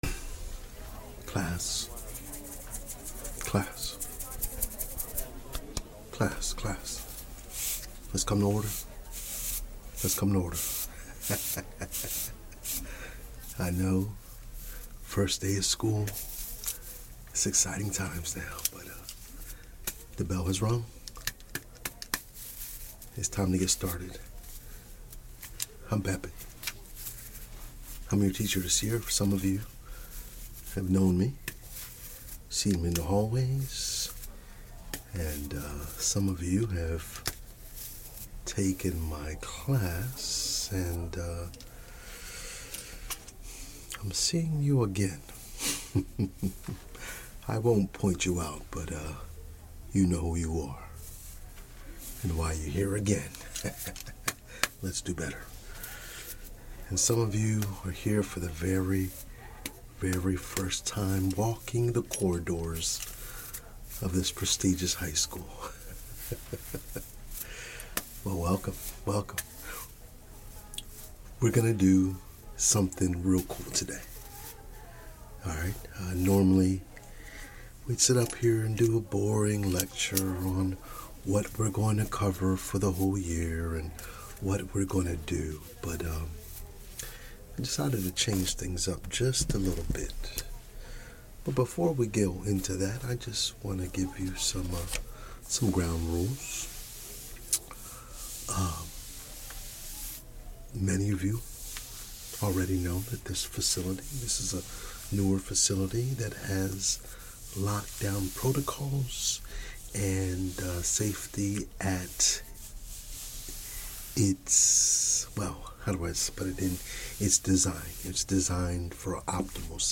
• Soft-spoken teacher roleplay with gentle ASMR triggers